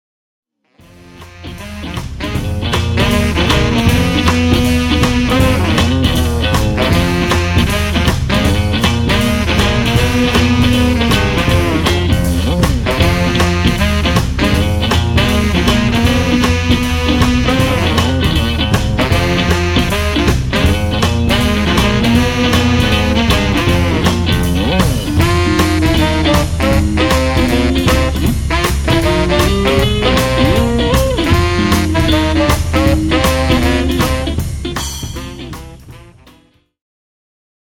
ジャズ、ジャイヴ、スウィング、ボサノヴァといったスタイルに敬意を払いつつも、結果的にはロックなサウンドに仕上がっている。